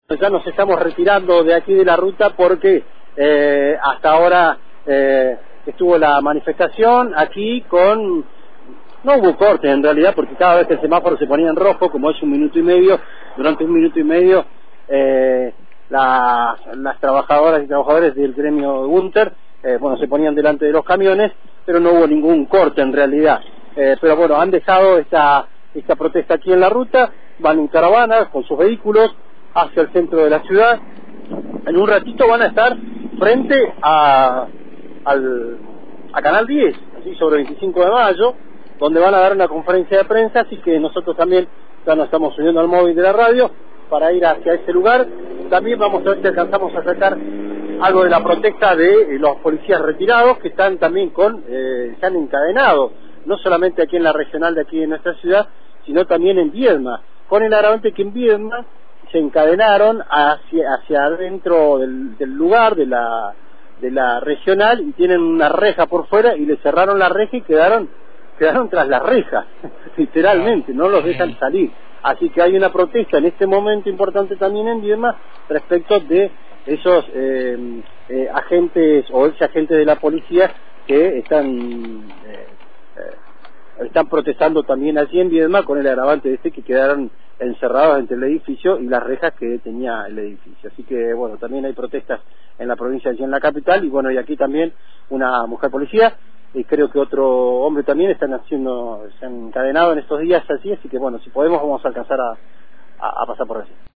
Escuchá la cobertura del móvil de Antena Libre al final de la actividad en Av. San Juan y ruta N.º 22: